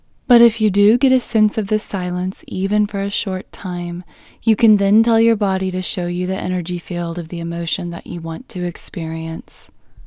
LOCATE OUT English Female 34